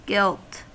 Ääntäminen
Ääntäminen US Tuntematon aksentti: IPA : /ɡɪɫt/ Haettu sana löytyi näillä lähdekielillä: englanti Gilt on sanan gild partisiipin perfekti.